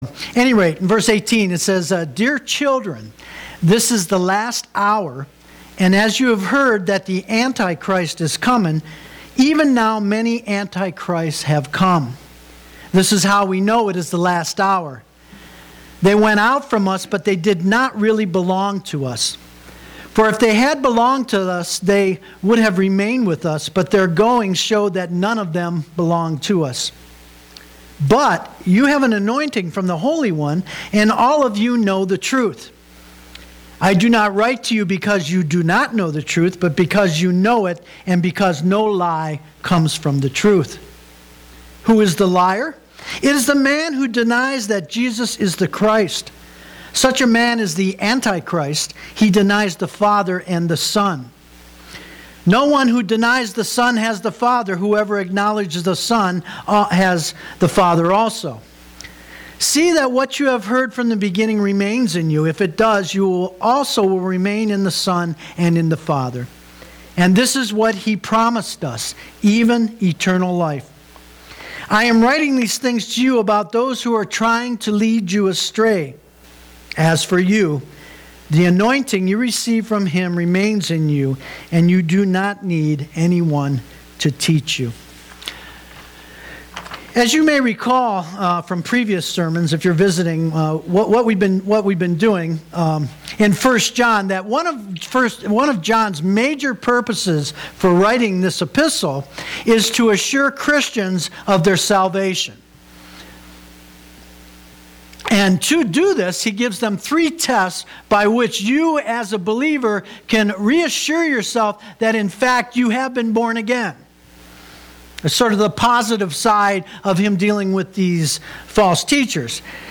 August 9, 2015 (Sunday Morning Service)